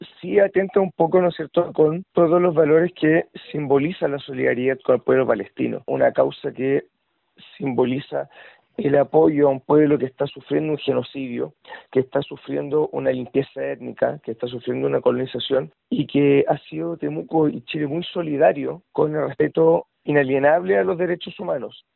Así lo detalló en conversación con La Radio